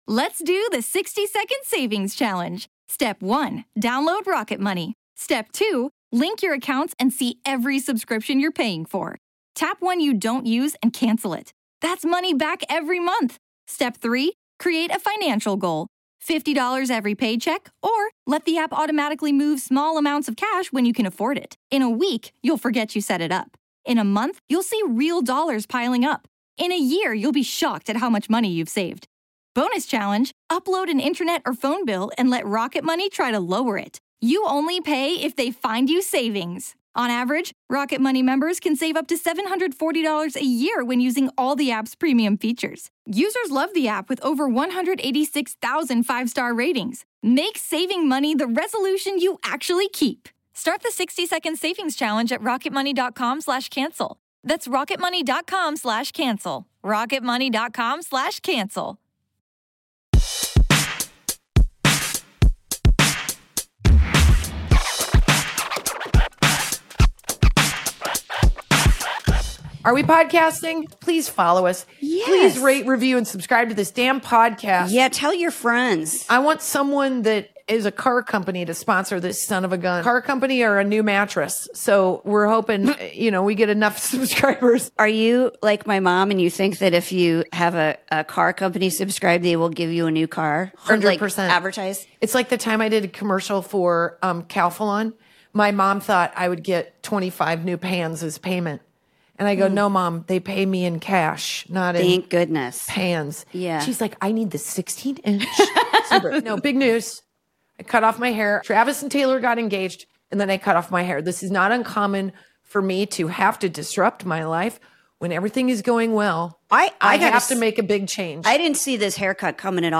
Female comedy duo